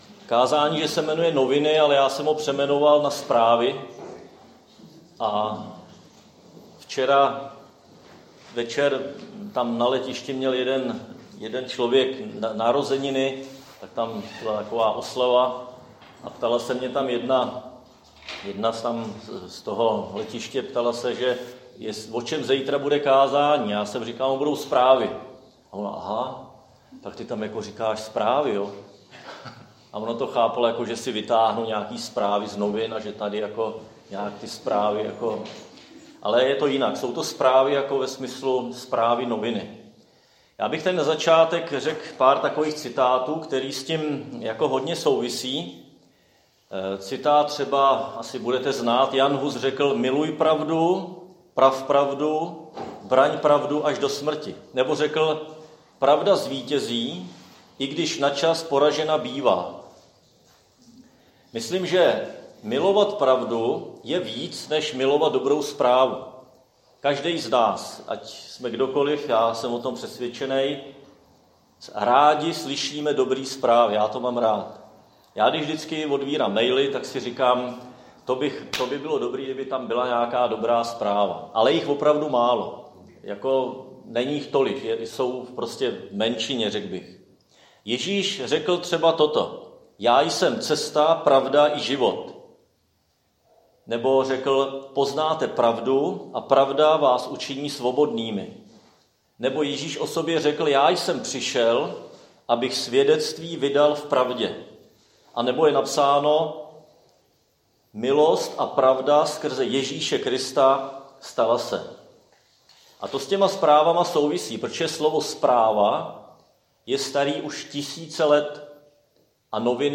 Křesťanské společenství Jičín - Kázání 6.6.2021